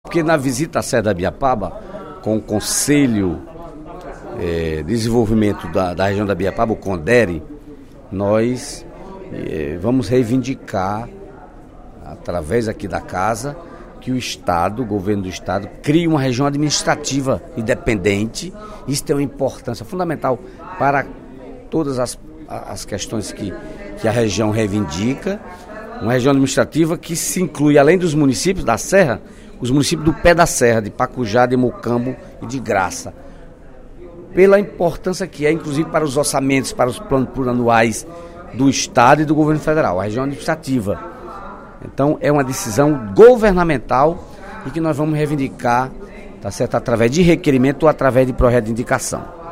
Durante o primeiro expediente da sessão plenária desta quarta-feira (28/05), o deputado Dedé Teixeira (PT) comentou a decisão do Tribunal Superior Eleitoral (TSE) de derrubar decreto legislativo promulgado pelo Congresso Nacional em 2013, e ratificou resolução da própria Corte que alterou a quantidade de deputados federais de 13 estados já para as eleições de outubro.